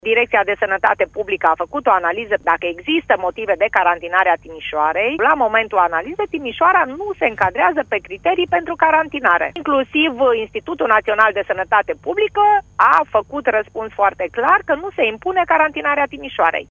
Prefectul a explicat, pentru Radio Timișoara, că analizele efectuate de specialiști arată că măsura nu este oportună, deși primarii localităților periurbane, care au fost plasate în carantină, susțin că numai așa se va reduce numărul de infectări cu virusul SARS COV 2.